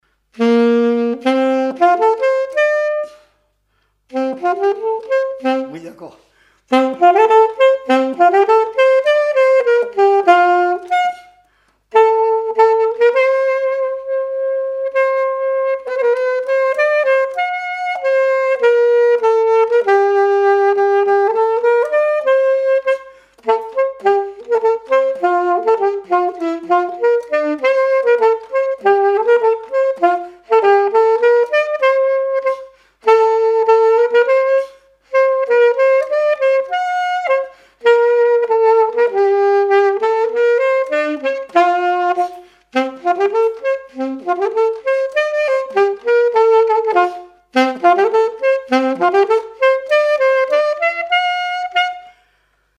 Thorigny ( Plus d'informations sur Wikipedia ) Vendée
danse : quadrille : pastourelle
Pièce musicale inédite